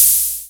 Wu-RZA-Hat 61.wav